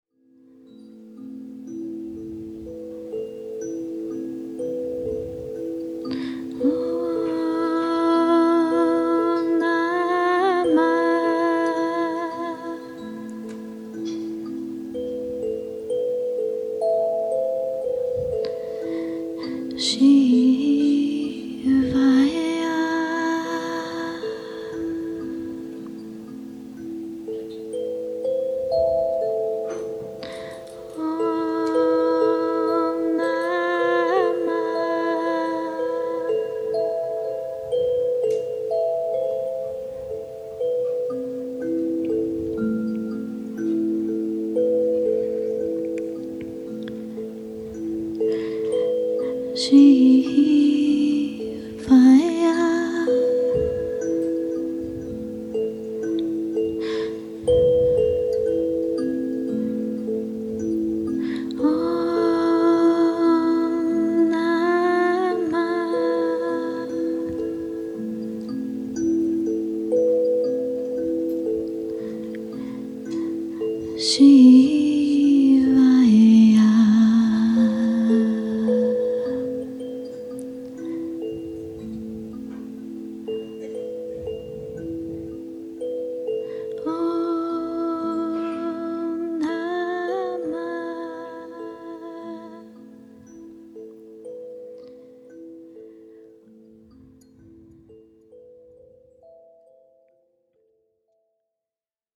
If so, join us for these 14 hours of nourishing, uplifting, and transformative highlights from the miraculous 5-day retreat.
Using the sansula (thumb piano) to create a sacred and intimate environment during the re-entry into the space following the dinner break
10 Om Namah Shivaya (sansula) - sample.mp3